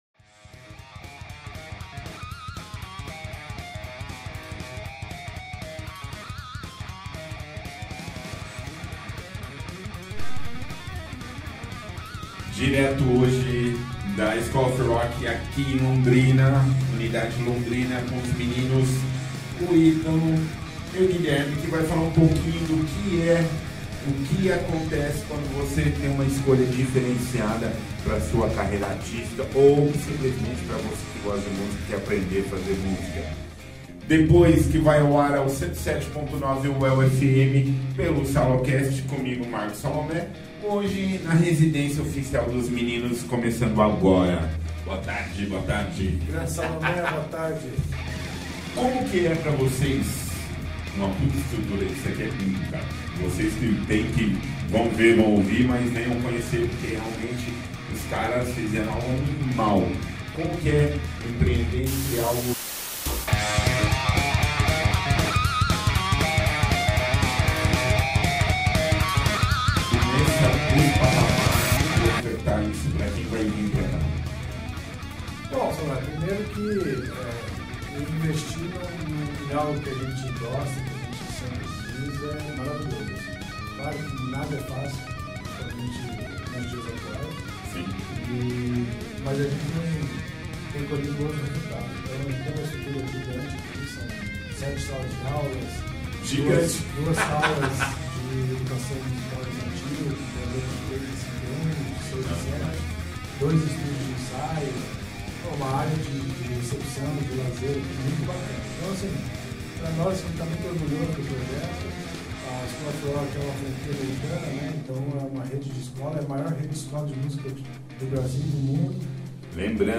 Estúdios UEL FM 107.9